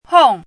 chinese-voice - 汉字语音库
hong4.mp3